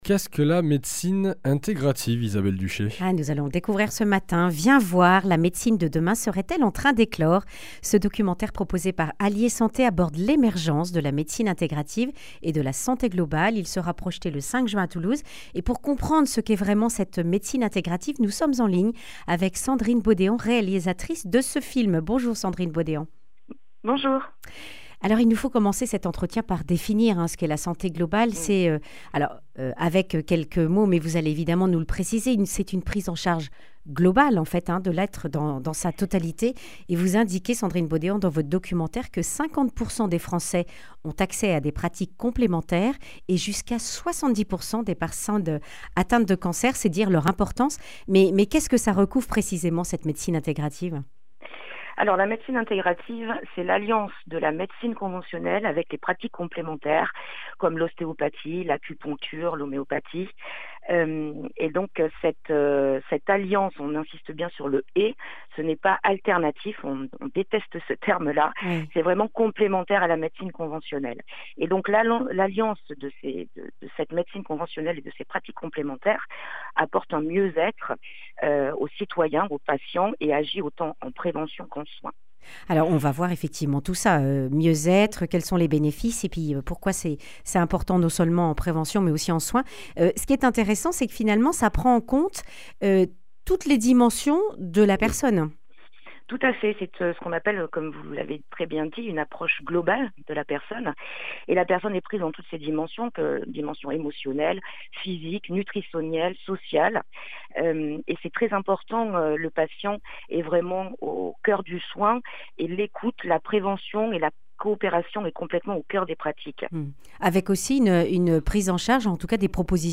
Accueil \ Emissions \ Information \ Régionale \ Le grand entretien \ La médecine intégrative, une prise en charge du patient dans toutes ses (…)